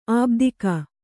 ♪ ābdika